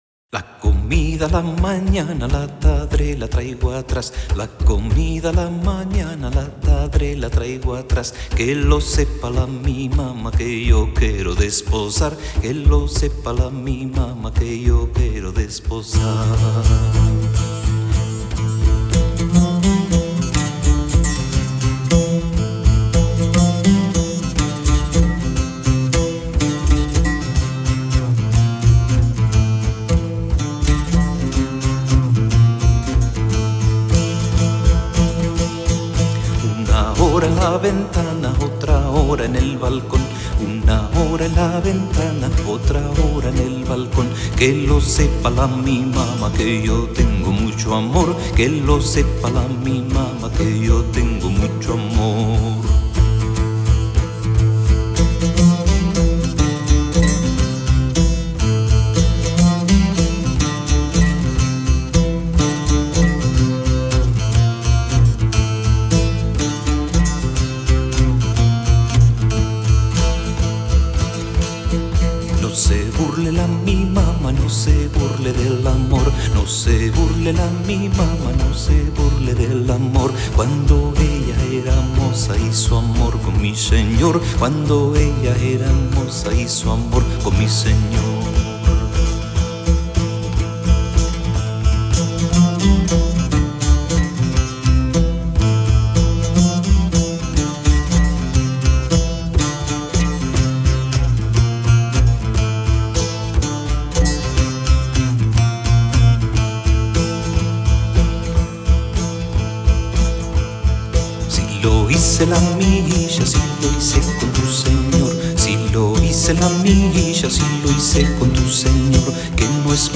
Laúd